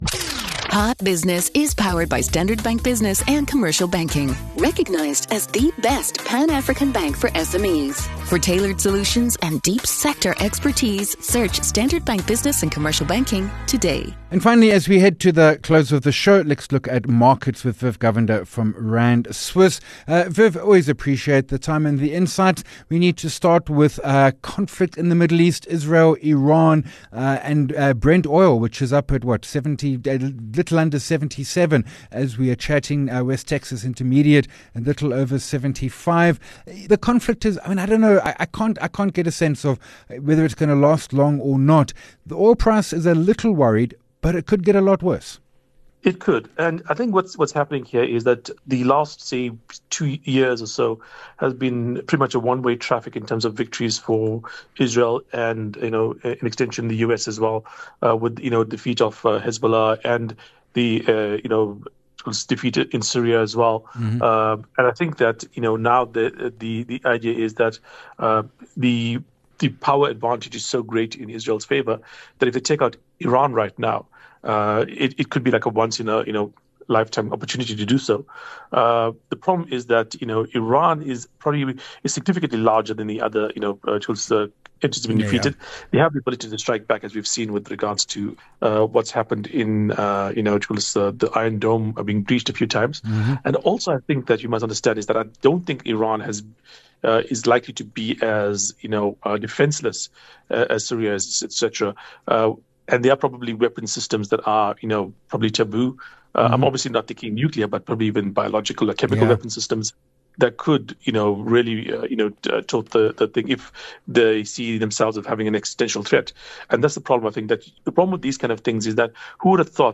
18 Jun Hot Business Interview